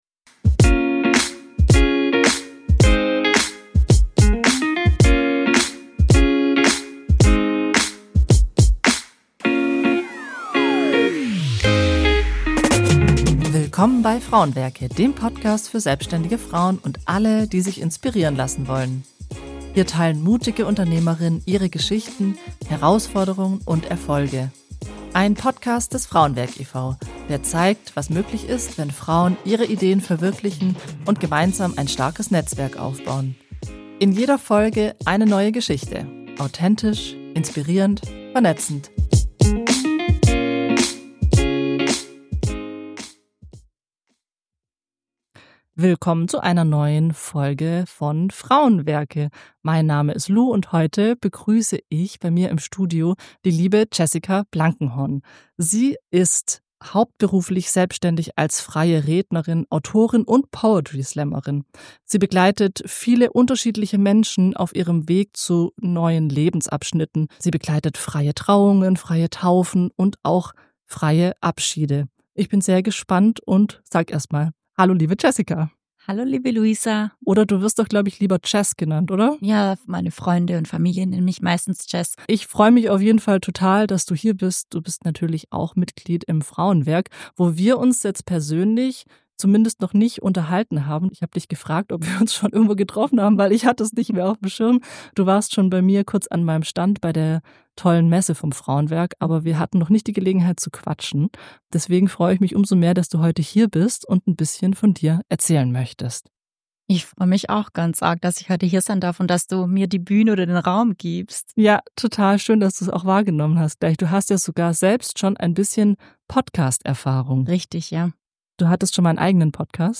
Ein ehrliches Gespräch über Neuanfänge, Verbundenheit und den Mut zur eigenen Stimme.